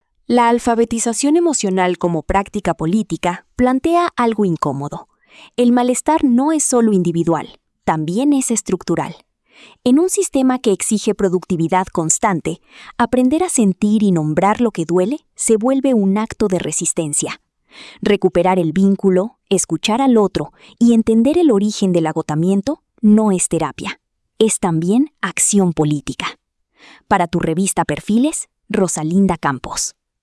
🎙 COMENTARIO EDITORIAL